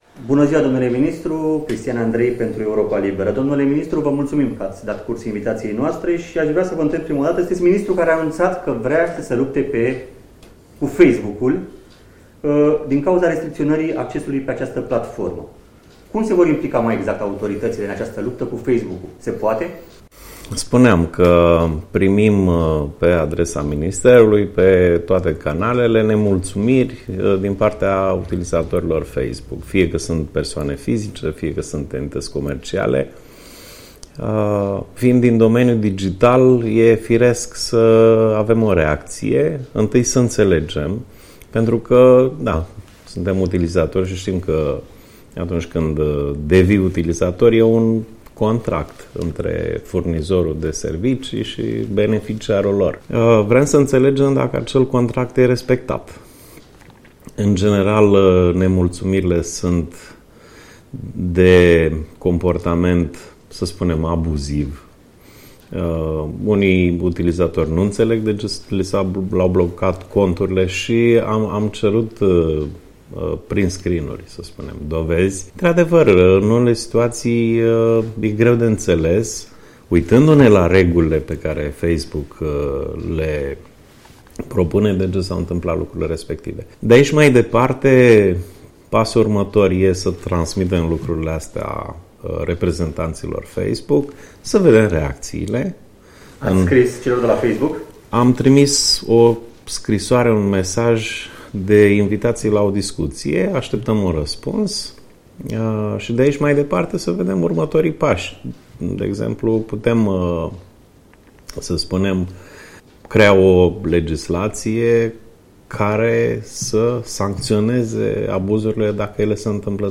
Interviu.